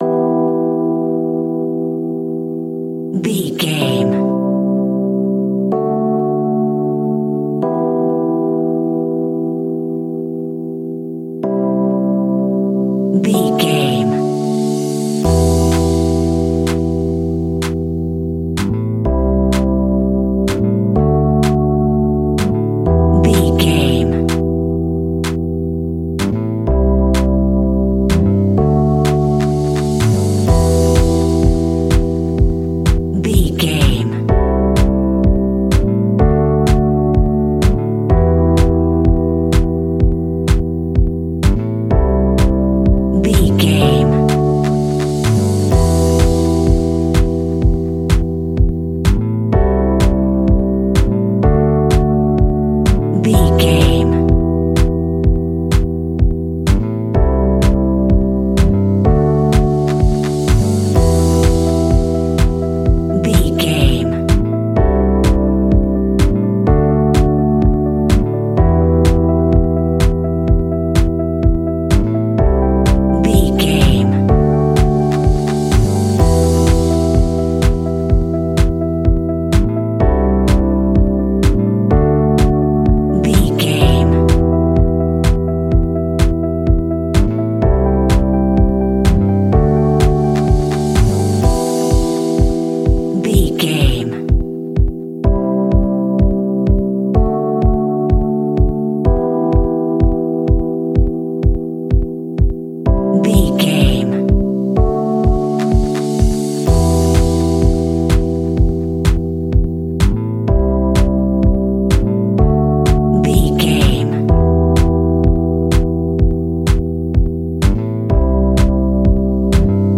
royalty free music
Aeolian/Minor
uplifting
energetic
bouncy
funky
bass guitar
synthesiser
electric piano
drum machine
funky house
deep house
nu disco
groovy
funky guitar
synth bass